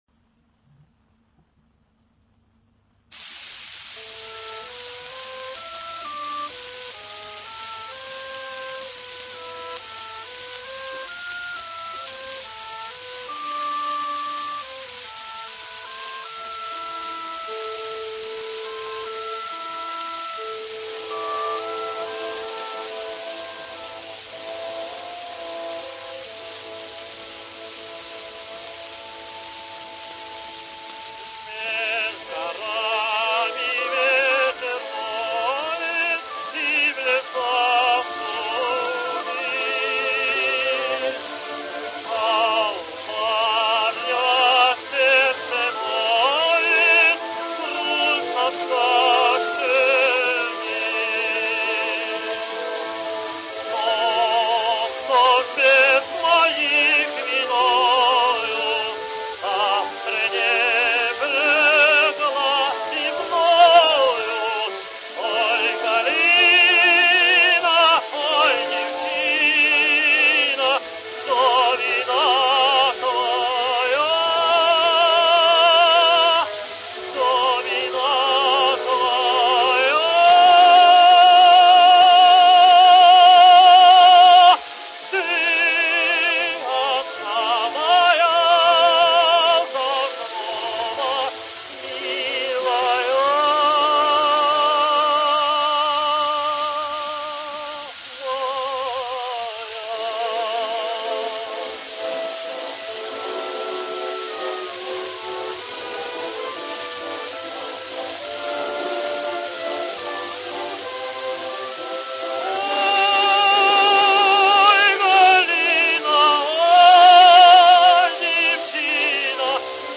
His voice had a range up to high F.
Andrej Labinskij sings Halka: